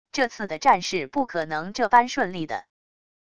这次的战事不可能这般顺利的wav音频生成系统WAV Audio Player